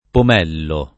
[ pom $ llo ]